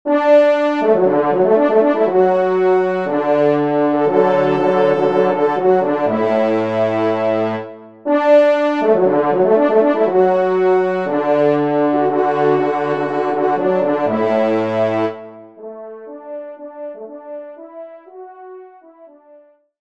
Genre :  Divertissement pour quatre Trompes ou Cors
Pupitre 4° Trompe/ Cor